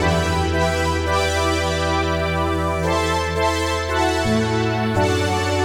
Index of /musicradar/80s-heat-samples/85bpm
AM_VictorPad_85-E.wav